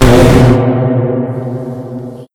pl_impact_airblast1.wav